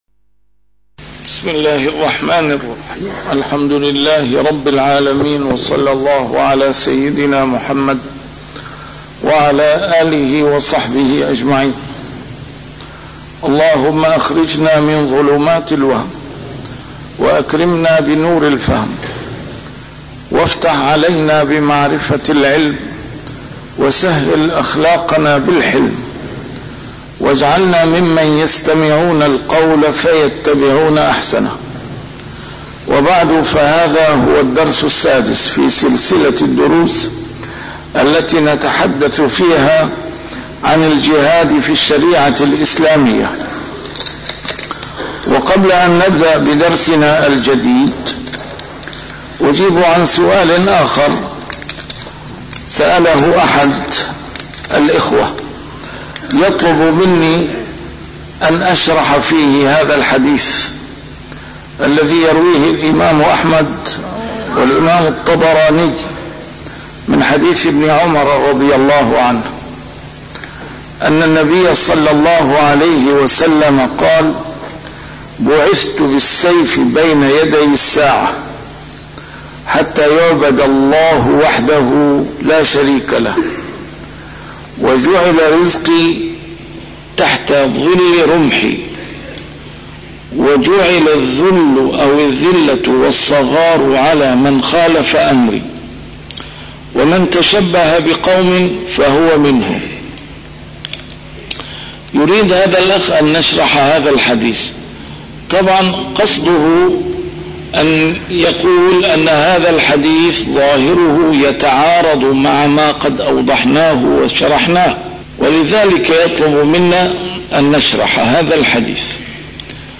A MARTYR SCHOLAR: IMAM MUHAMMAD SAEED RAMADAN AL-BOUTI - الدروس العلمية - الجهاد في الإسلام - تسجيل قديم - الدرس السادس: مسؤولية الدعوة - ضوابطها وأهدافها